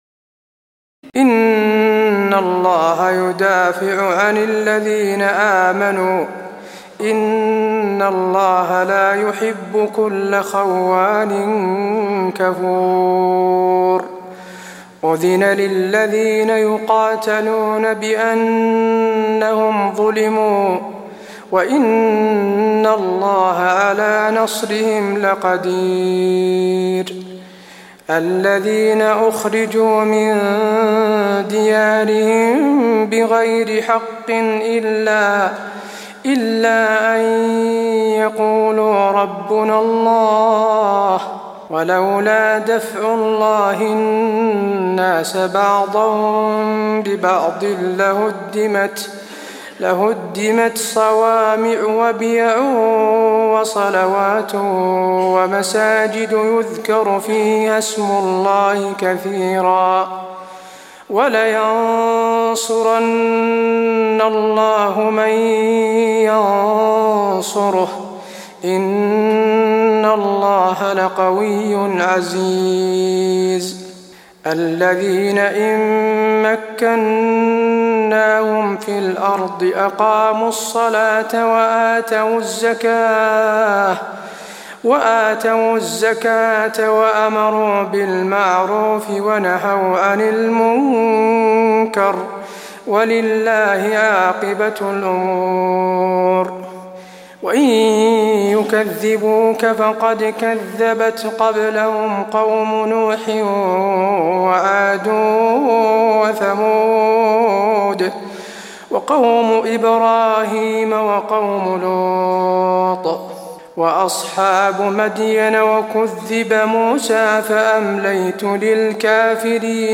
تراويح الليلة السادسة عشر رمضان 1423هـ من سورة الحج (38-78) Taraweeh 16 st night Ramadan 1423H from Surah Al-Hajj > تراويح الحرم النبوي عام 1423 🕌 > التراويح - تلاوات الحرمين